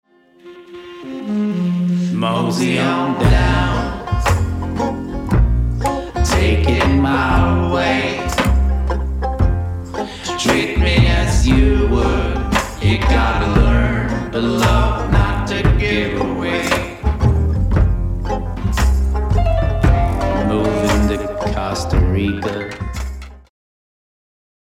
• Recorded at Blue Whale Recording Studios, Berlin